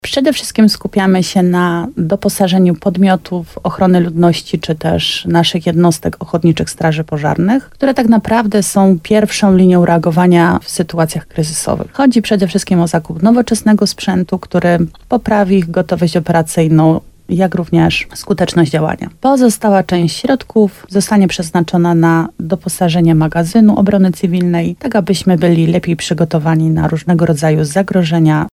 – Aktualnie złożony do wojewody wniosek zakłada przede wszystkim wsparcie dla jednostek OSP – mówiła w programie Słowo za Słowo na antenie RDN Nowy Sącz, wójt gminy Łabowa Marta Słaby.